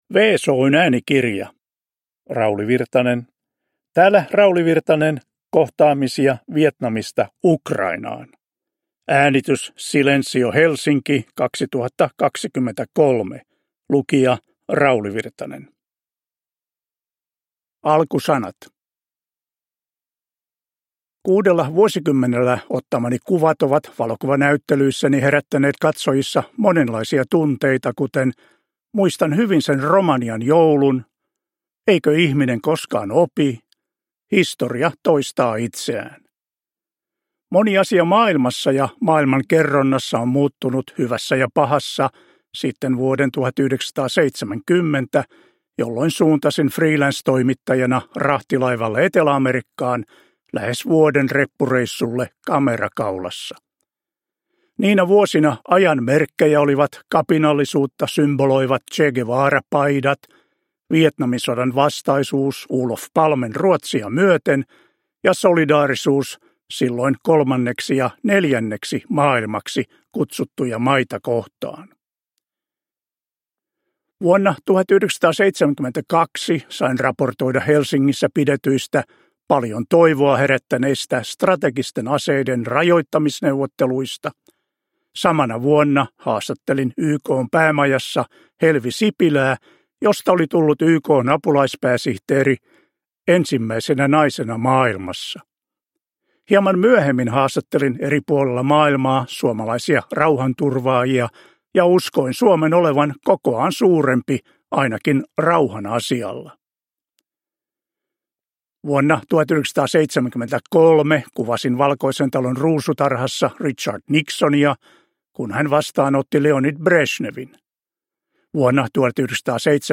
Täällä Rauli Virtanen – Ljudbok – Laddas ner
Uppläsare: Rauli Virtanen